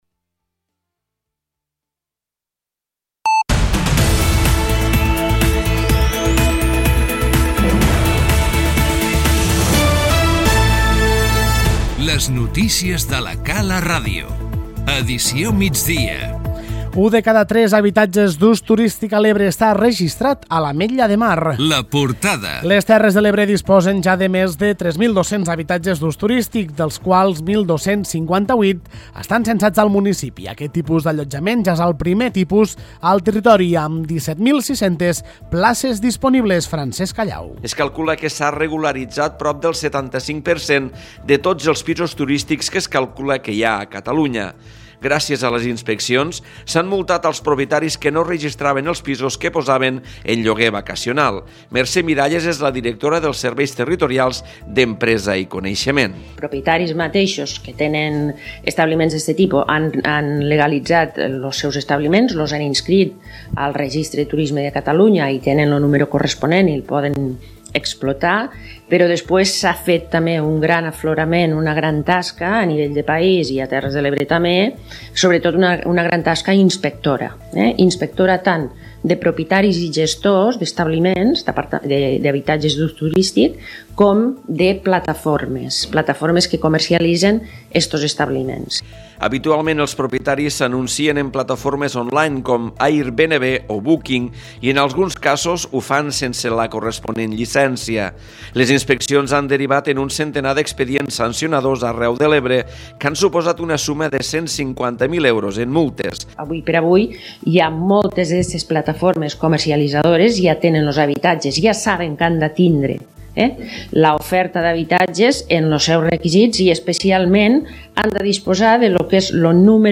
Les notícies migdia 12/01/2018